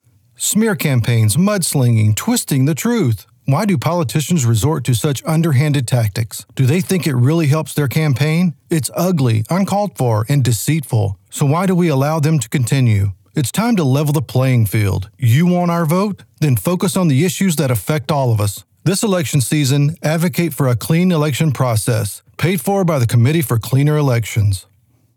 Cleaner Elections Political Ad_1.29.25_1_0.mp3
Political Advert
Voice Age